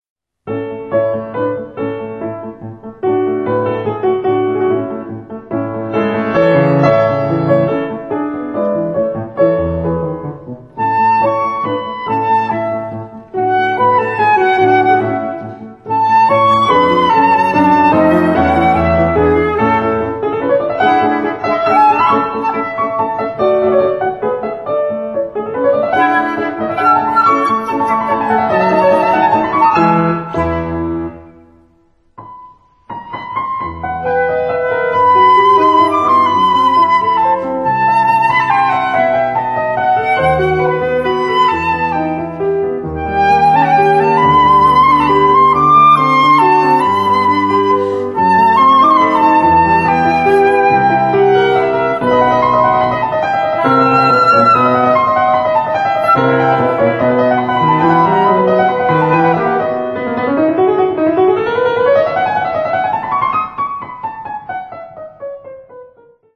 Bohuslav MARTINU: Concerto for Piano Trio and String Orchestra, H.231; u.a.
Suk Kammerorchester